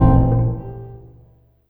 59 FX 1   -R.wav